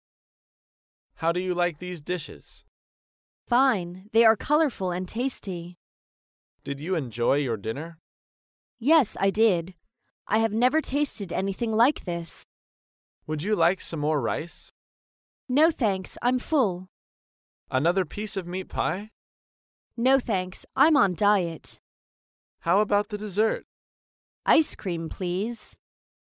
在线英语听力室高频英语口语对话 第227:饱餐一顿的听力文件下载,《高频英语口语对话》栏目包含了日常生活中经常使用的英语情景对话，是学习英语口语，能够帮助英语爱好者在听英语对话的过程中，积累英语口语习语知识，提高英语听说水平，并通过栏目中的中英文字幕和音频MP3文件，提高英语语感。